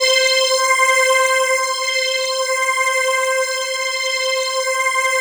DM PAD3-07.wav